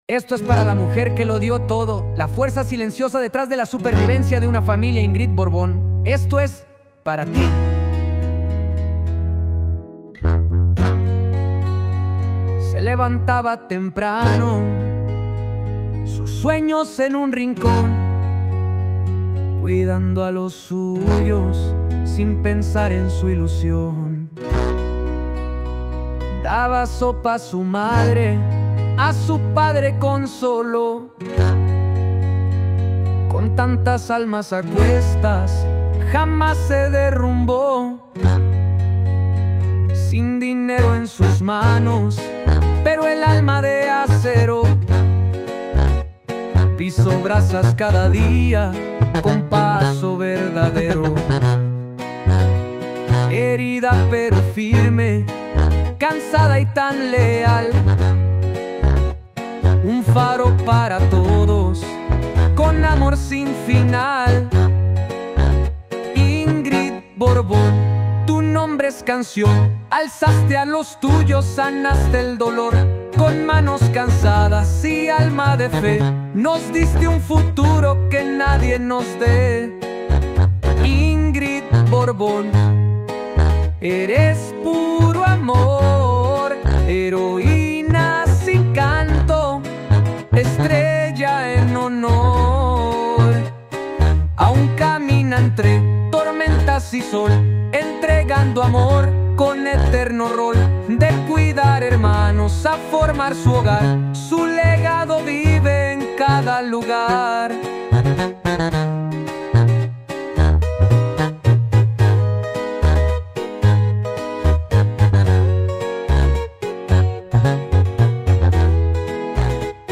un poderoso Corrido Tumbado